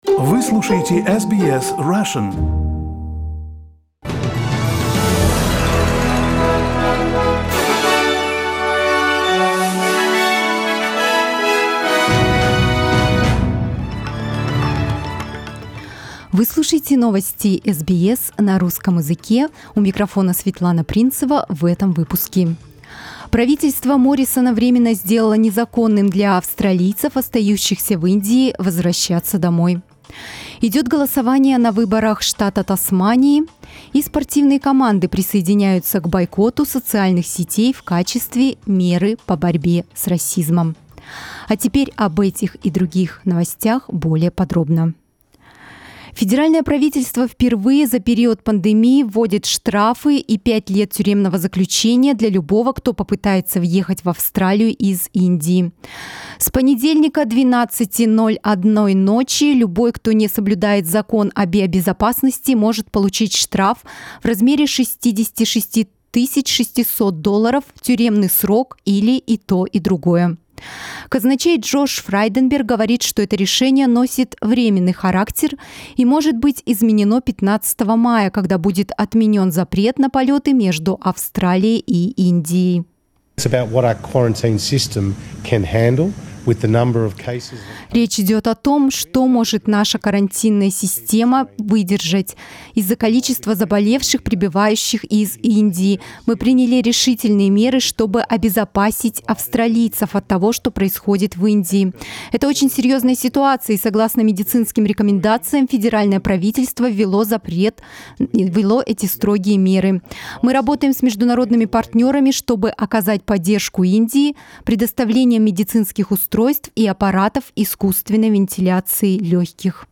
Новости SBS на русском языке - 01.05